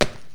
jump_1.wav